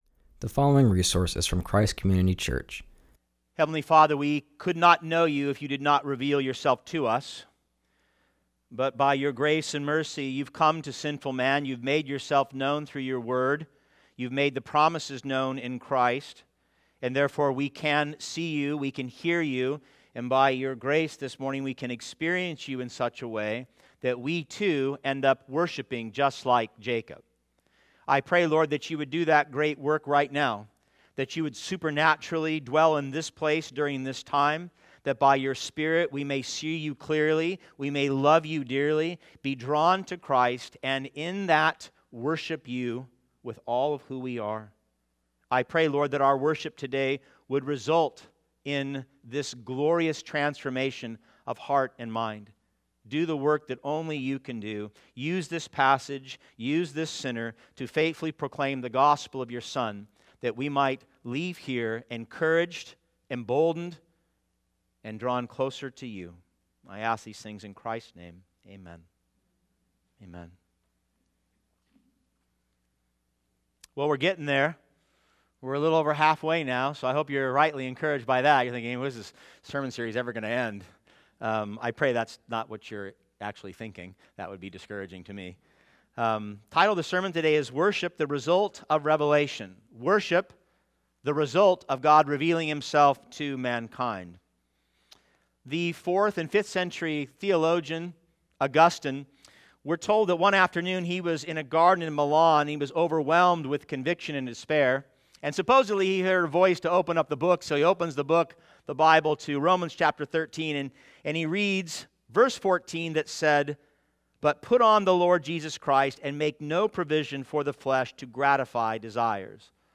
preaches from Genesis 28:10-22.